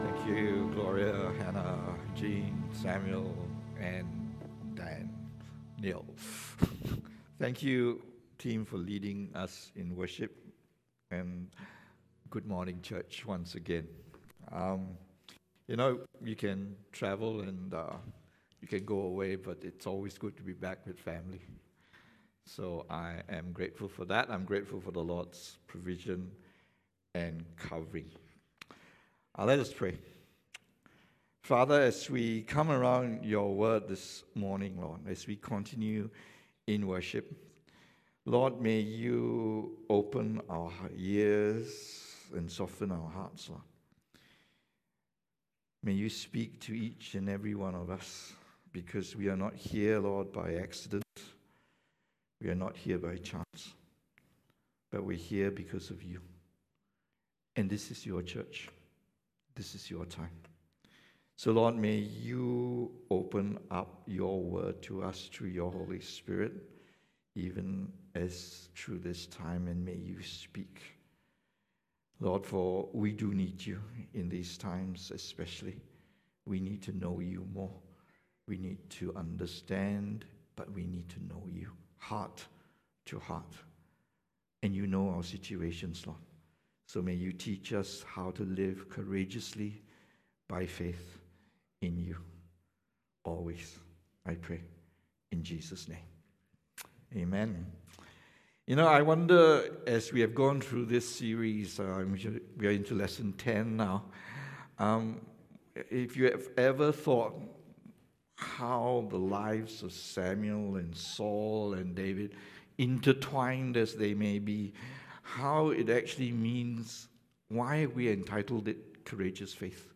English Sermons | Casey Life International Church (CLIC)